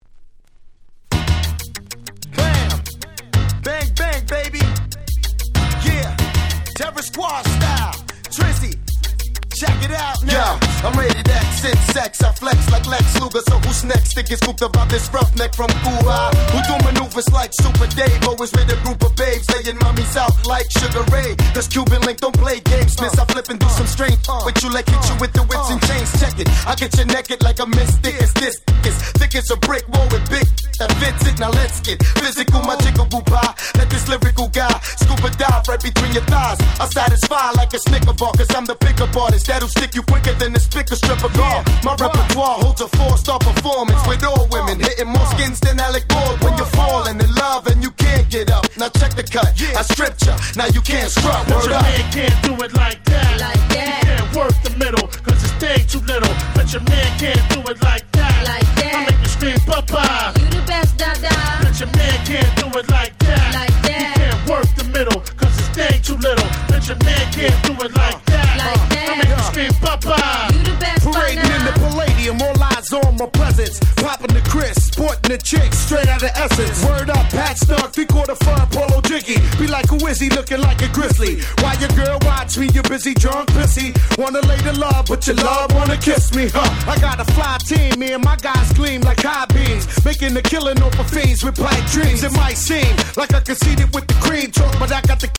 98' Smash Hit Hip Hop !!
90's Boom Bap ブーンバップ